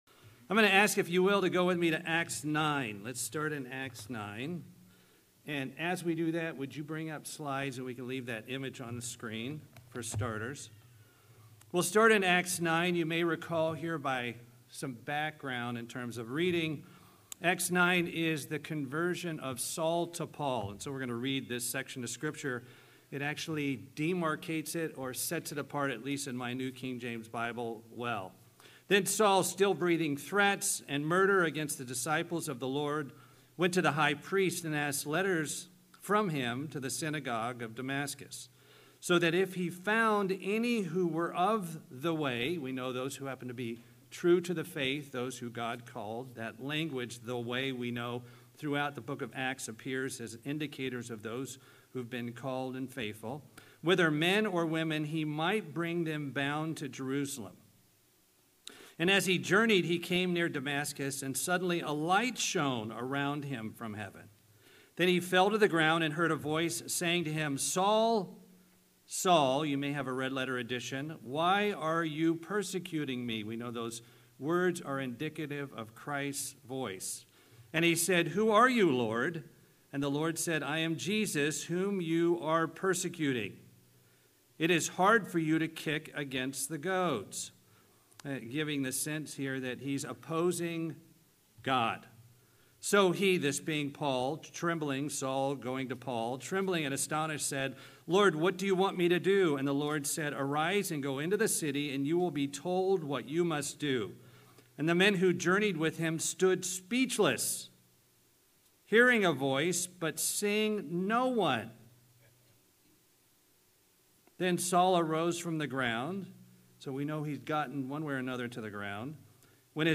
Do you have any goals for the next year? This sermon encourages God's people to set personal goals - spiritual and physical - for the next 12 months.